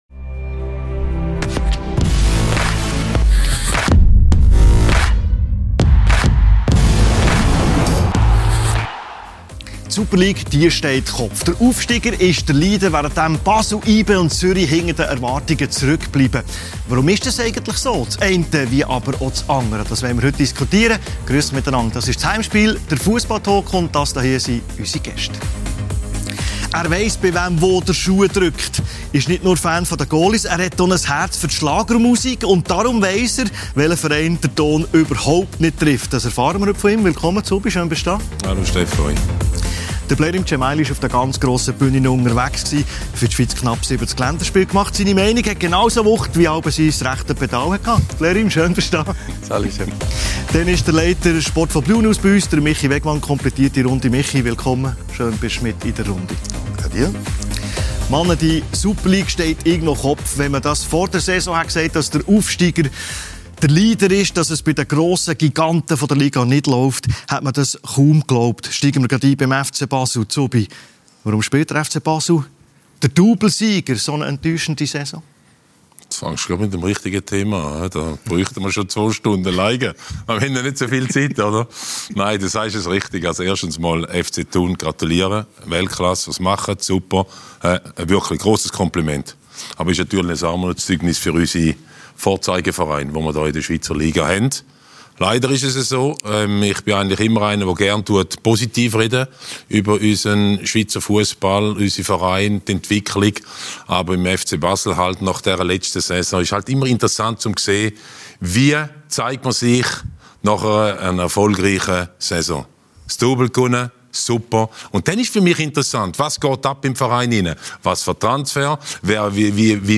Die Super League steht Kopf – mit Pascal Zuberbühler & Blerim Dzemaili ~ Heimspiel ⎥ Der Fussball-Talk Podcast